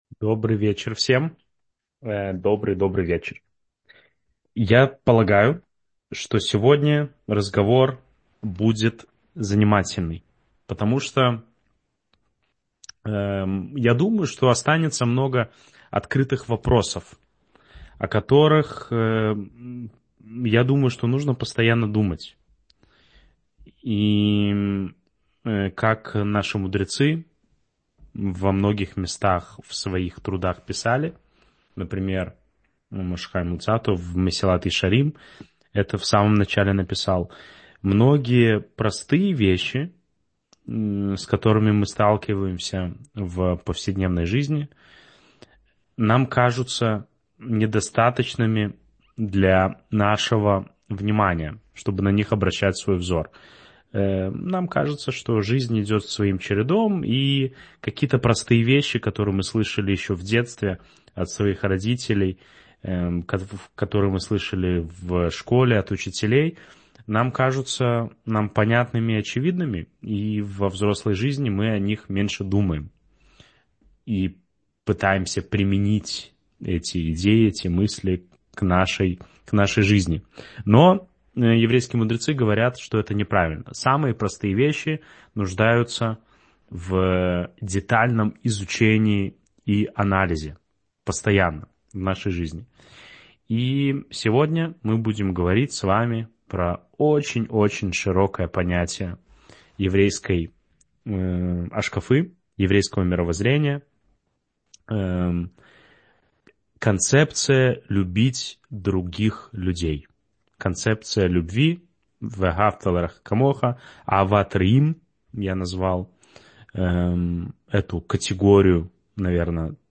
Аудиоуроки